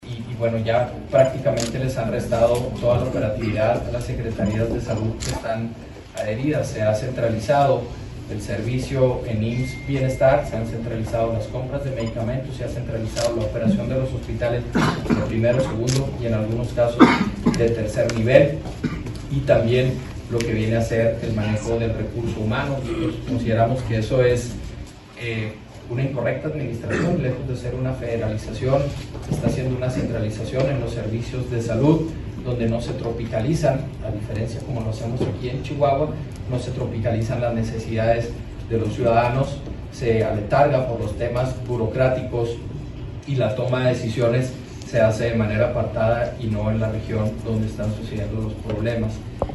AUDIO: GILBERTO BAEZA MENDOZA, TITULAR DE LA SECRETARÍA DE SALUD DEL ESTADO